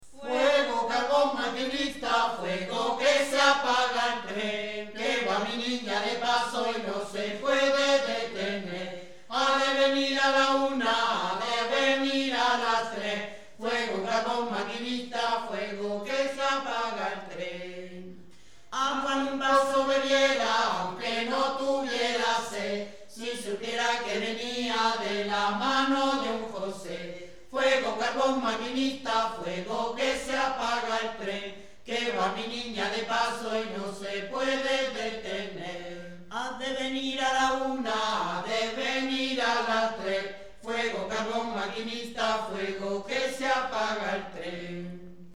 A continuación os transcribimos algunas de ellas y las acompañamos del sonido original en las gargantas de algunos de aquellos "mozos" que ya no lo son tanto...
Se cantaba en las ruedas de las viuditas cuando encartaba.